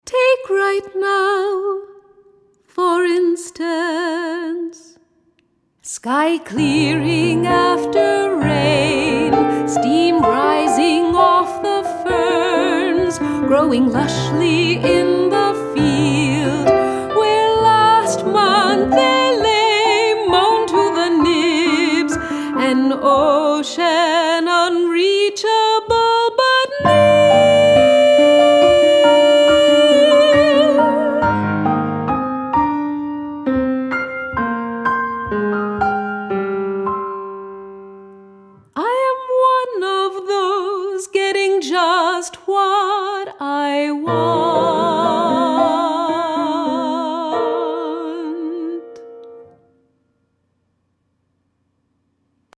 A song cycle for soprano and piano